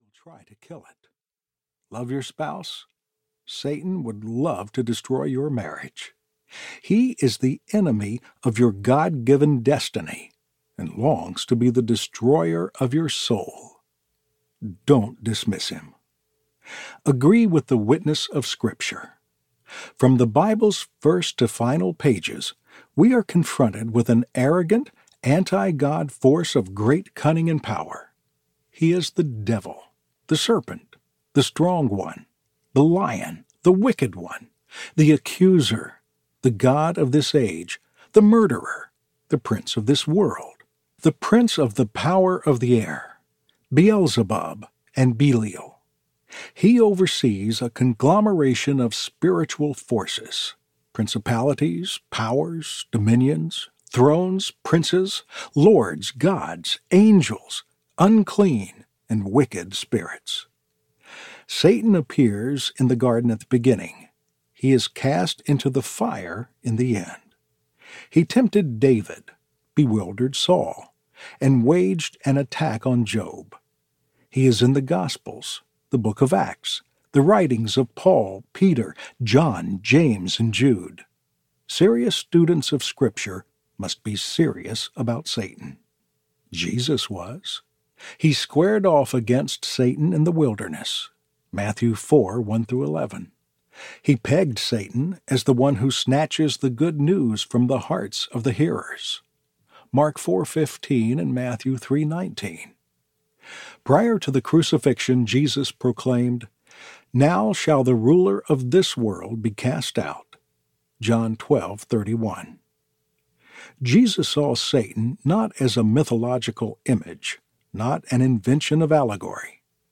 Unshakable Hope Audiobook